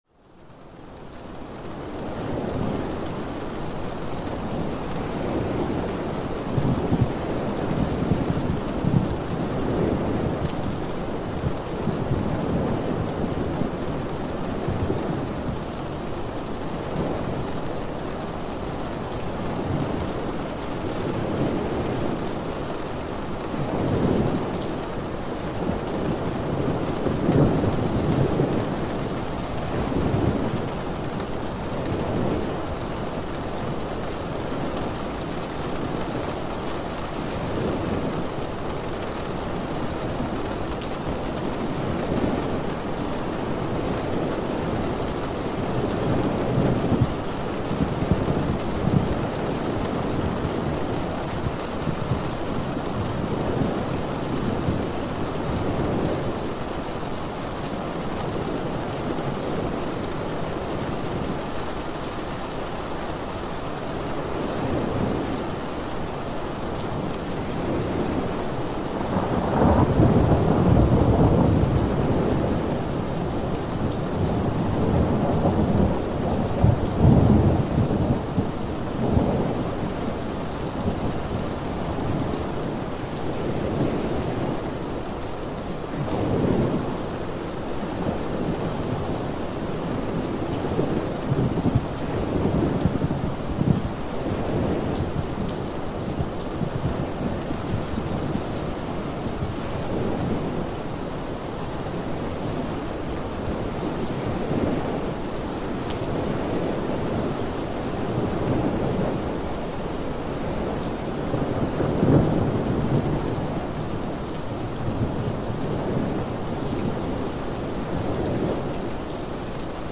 Calm Rain Outside Your Room – Sleep Better
Every episode of Rain Sounds is carefully crafted to deliver high-quality ambient rain recordings that promote deep sleep, reduce anxiety, and enhance mindfulness.